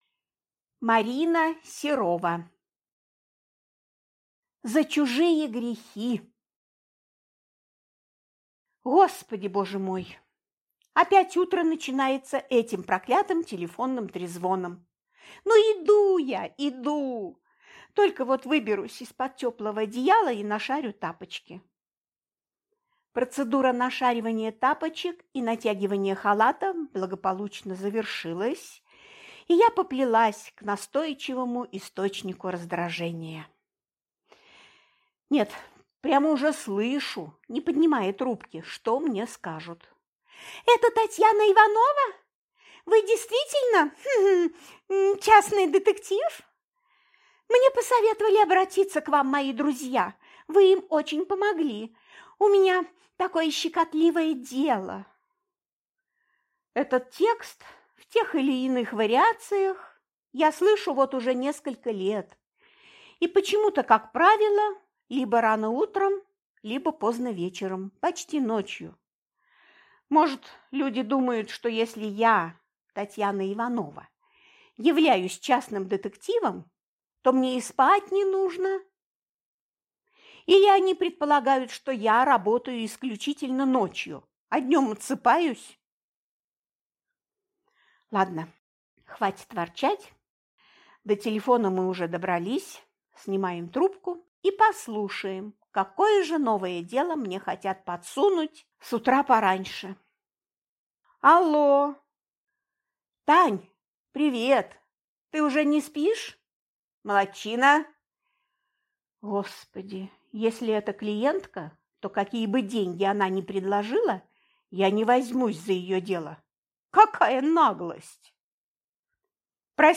Аудиокнига За чужие грехи | Библиотека аудиокниг